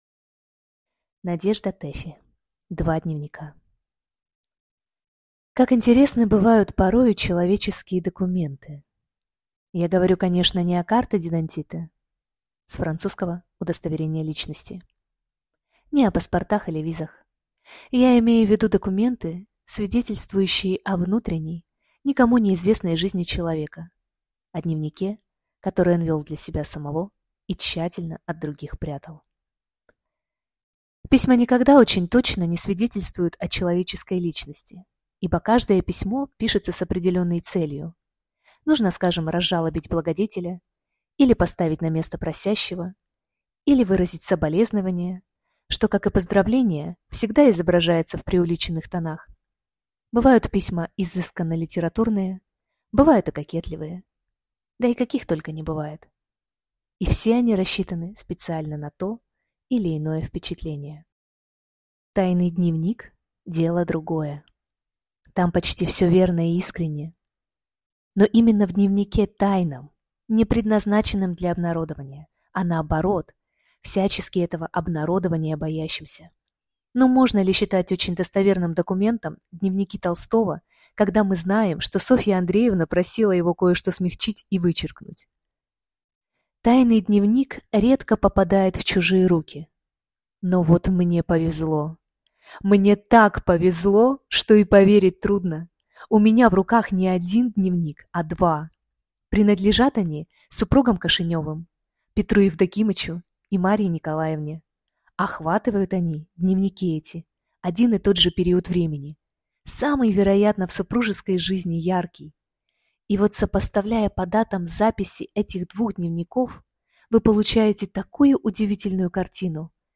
Аудиокнига Два дневника | Библиотека аудиокниг